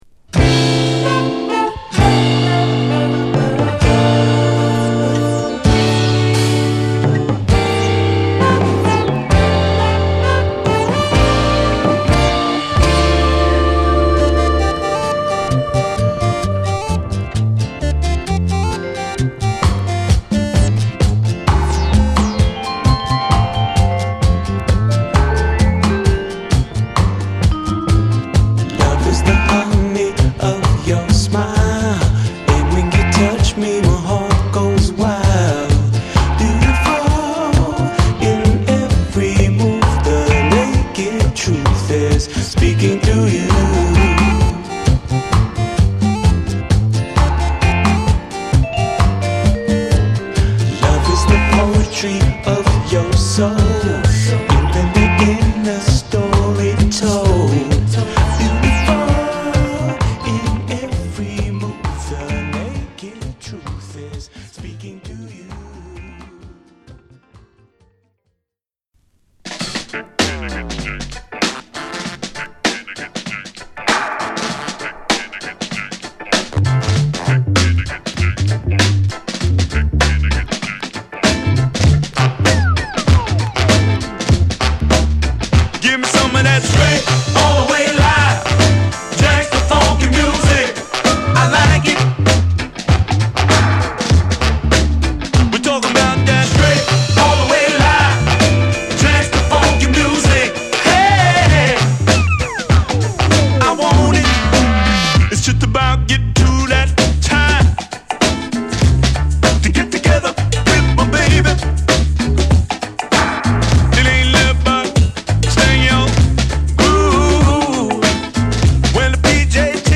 中盤にブレイクも有り
南国ムード全開のヴィブラフォン・アレンジが素晴らし過ぎます